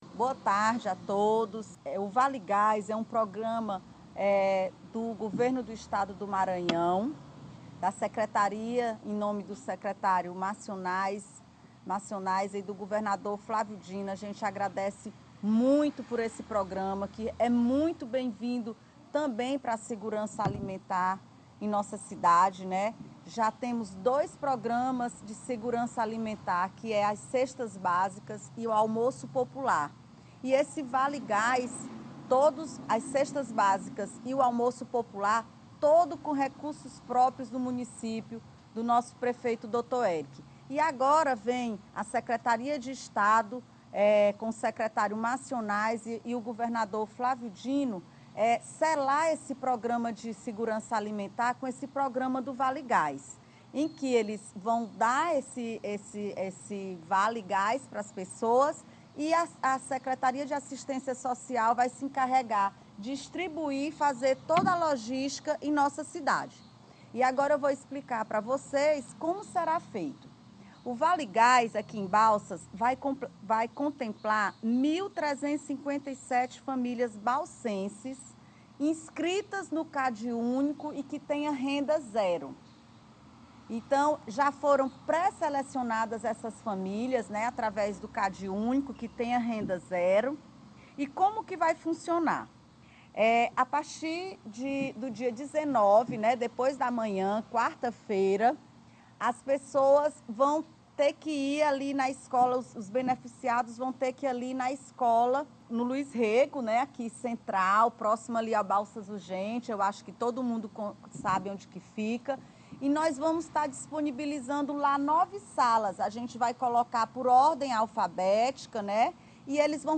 A primeira-dama e Secretária de Ação Social do município de Balsas, Vivianne Martins Coelho, dispôs-se de uma coletiva na manhã desta segunda-feira, 17/05, para divulgar os locais para cadastramento das famílias que almejam adquirir o vale gás.